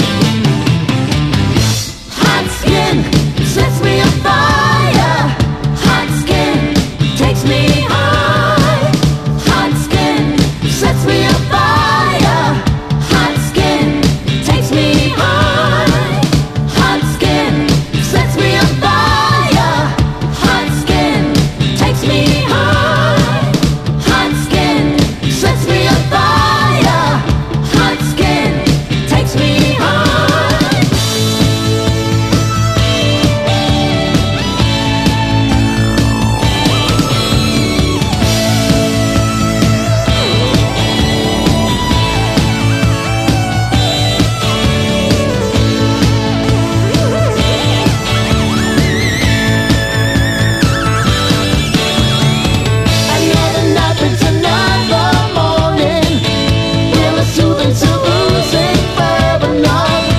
SURF / HOT ROD / GARAGE ROCK / GARAGE PUNK
オランダのローカル・サーフ・ロック・オムニバス！